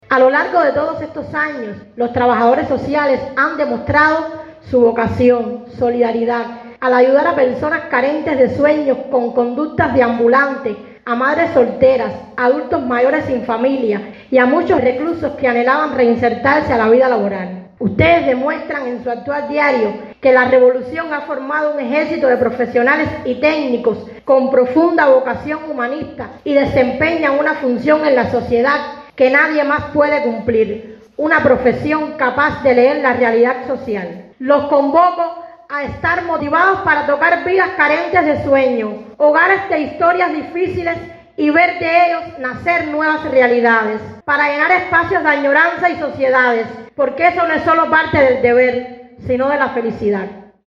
PEDRO BETANCOURT.- El barrio en transformación Cuba Libre, de este municipio, devino escenario para celebrar un emotivo acto político-cultural en saludo al XXIII aniversario de la creación del Programa Nacional de Trabajadores Sociales.